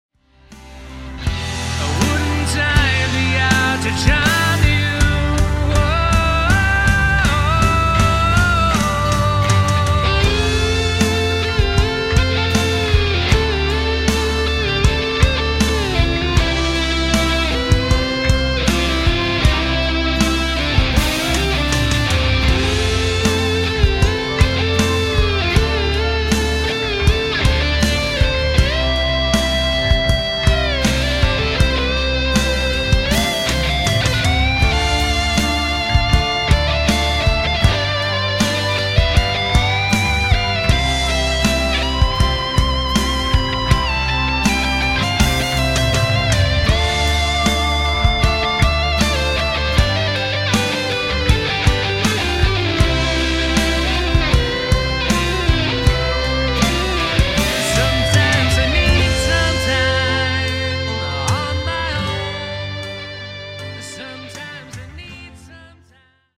Guitar Solo- Electric Violin Cover